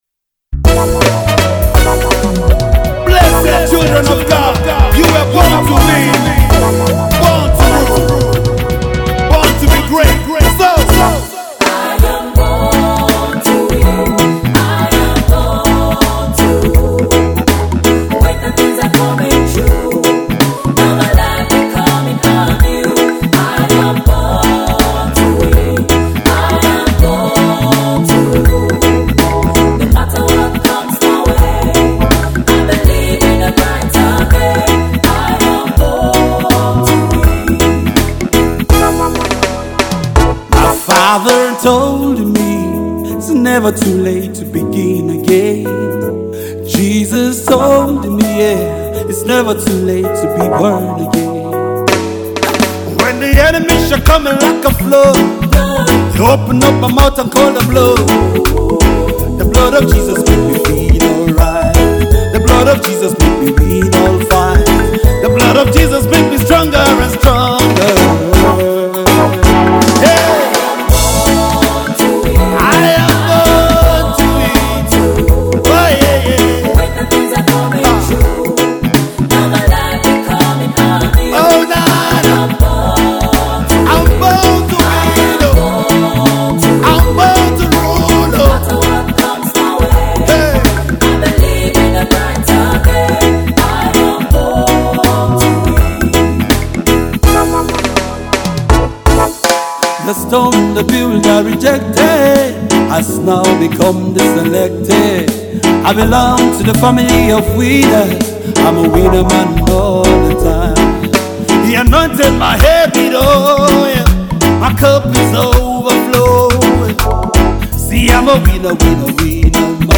Get ready to be uplifted by the soulful vibes